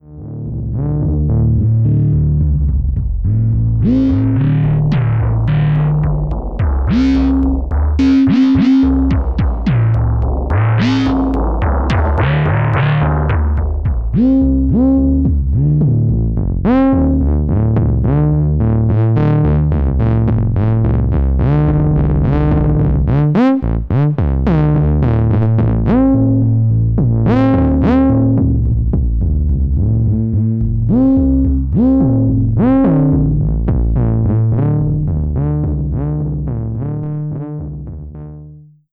FLAME "FM KOSMOS" Quad polyphonic FM synthesizer
8 - BassSynth Sequence
8_BassSynth_Sequence.wav